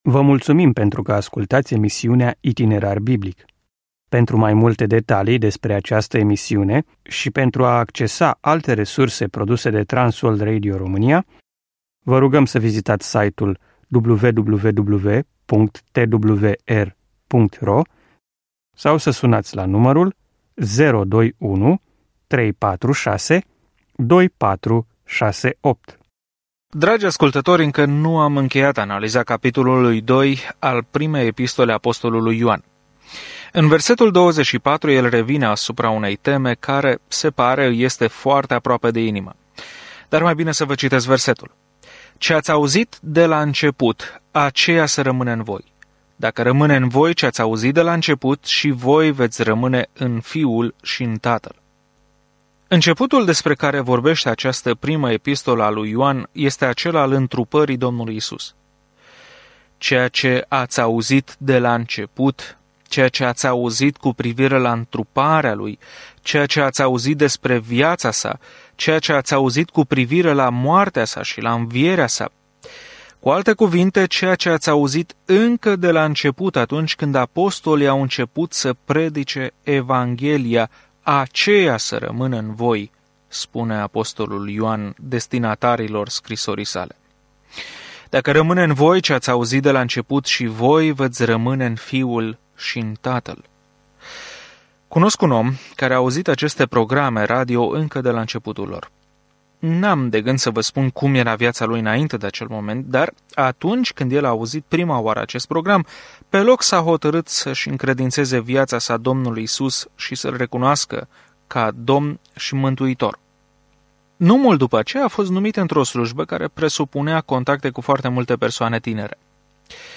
Scriptura 1 Ioan 2:24-29 Ziua 8 Începe acest plan Ziua 10 Despre acest plan Nu există cale de mijloc în această primă scrisoare a lui Ioan - fie alegem lumina sau întunericul, adevărul în fața minciunii, iubirea sau ură; Îl îmbrățișăm pe unul sau pe altul, așa cum fie credem, fie nepădăm pe Domnul Isus Hristos. Călătoriți zilnic prin 1 Ioan în timp ce ascultați studiul audio și citiți versete selectate din Cuvântul lui Dumnezeu.